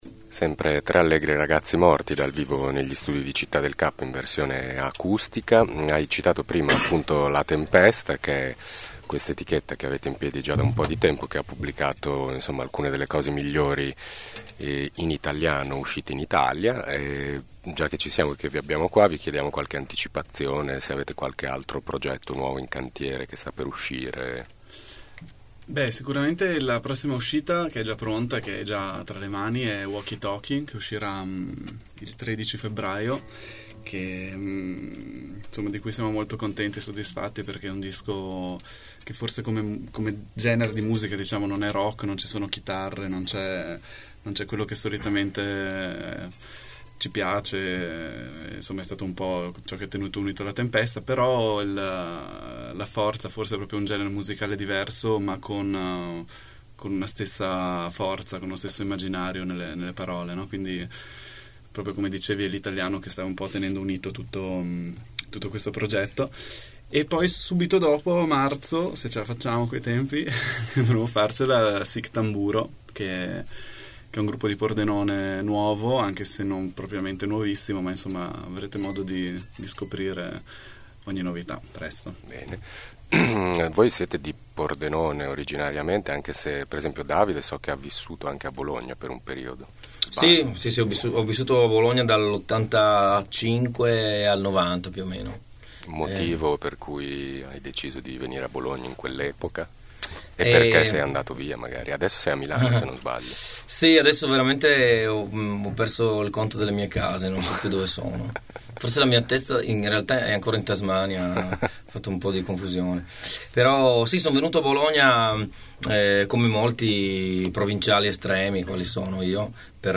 In occasione del loro concerto all’Estragon sabato scorso, sono venuti a trovarci a Thermos i Tre Allegri Ragazzi Morti.
tre-allegri-ragazzi-morti-intervista-3.mp3